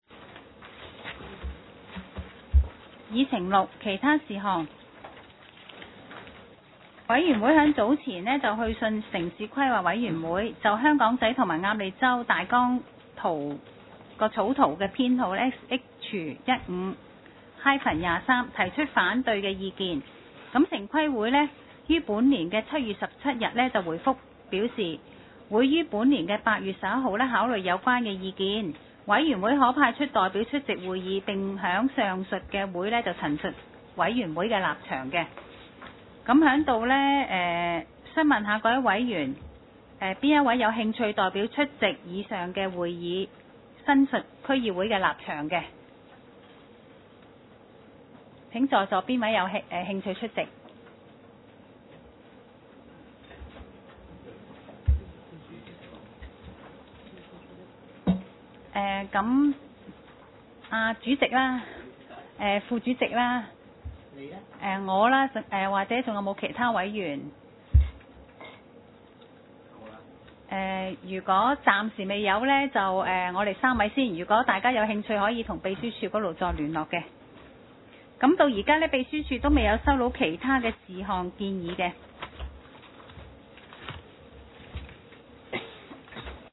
Agenda for the 19th Meeting of the
地點  ﹕ 香港 Venue : SDC Conference Room,